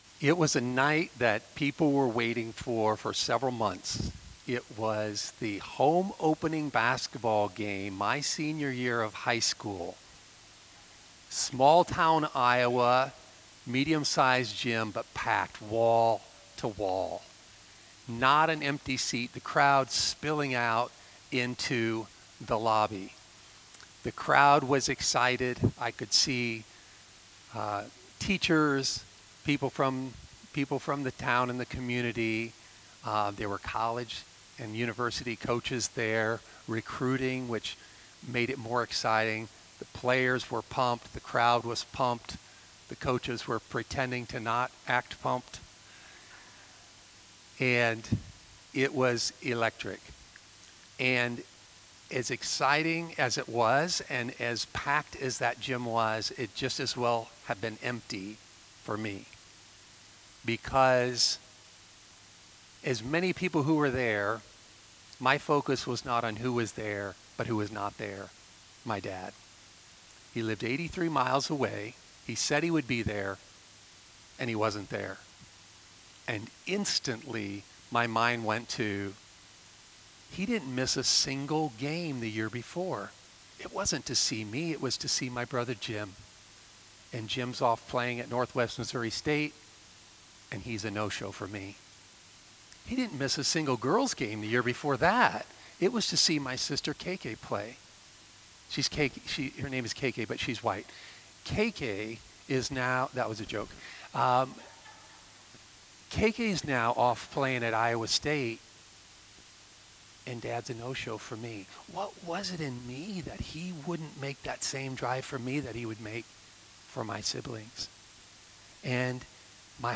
Sermon-Love-Moved-In-2-8-26.mp3